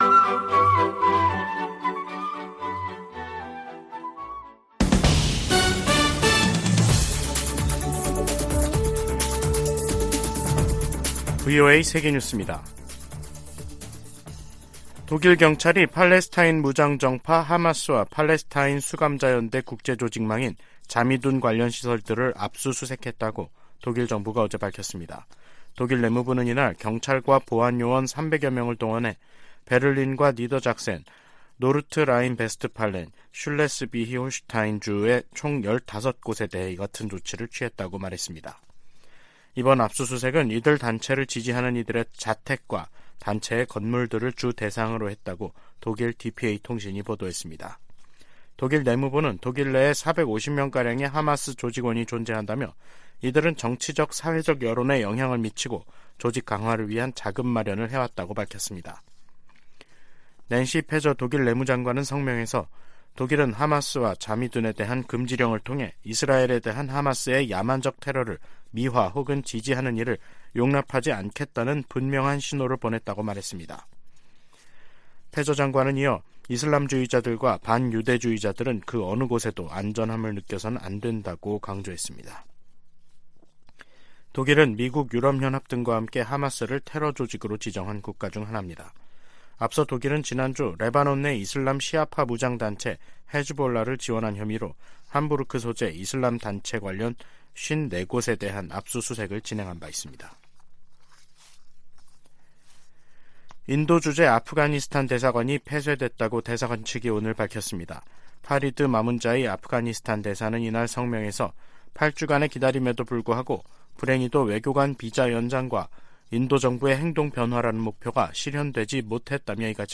VOA 한국어 간판 뉴스 프로그램 '뉴스 투데이', 2023년 11월 24일 2부 방송입니다. 미국과 유럽연합(EU)이 국제원자력기구 정기이사회에서 북한의 무기개발을 규탄했습니다. 북한이 쏴 올린 정찰위성 만리경 1호가 빠른 속도로 이동하면서 하루에 지구를 15바퀴 돌고 있는 것으로 확인됐습니다. 한국-영국 정부가 북한 해킹 공격의 위험성을 알리는 합동주의보를 발표했습니다.